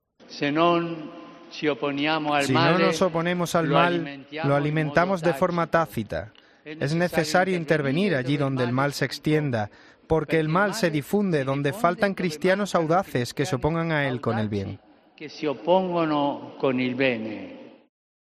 Rezo del Ángelus
El Santo Padre  realizó esta invitación antes del rezo del Ángelus dominical en la plaza de San Pedro, al que asistieron 90.000 fieles, según cifras de la Gendarmería Vaticana, entre ellos miles de jóvenes italianos llegados en peregrinación desde todo el país.